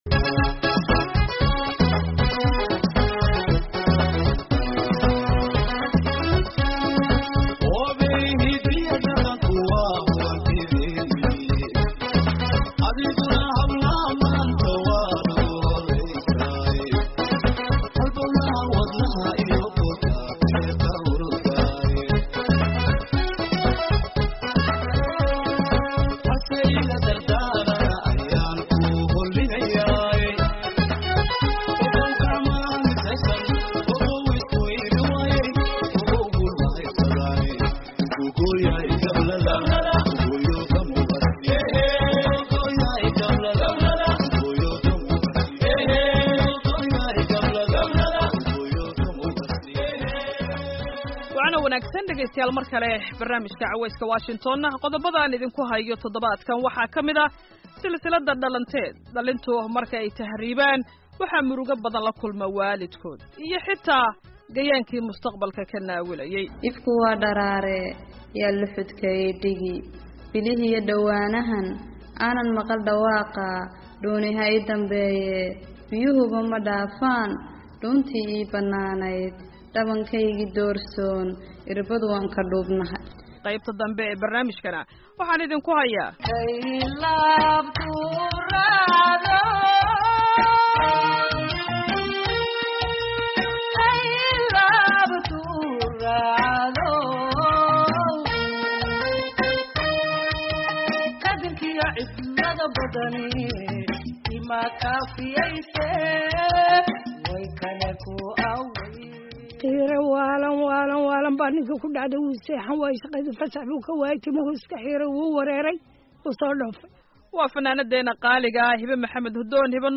Barnaamijka Cawayska Washington ee todobaadkan waxaad ku maqli doontaan qayb ka mid ah silsilaadii Dhalanteed oo dhibaatada tahriibka ka hadlaysa iyo Wareysi aanu la yeelanay fanaanadda Caanka ah ee Hbo Maxamed oo sheekeyneysaa taariikhda heesta KAFTAN.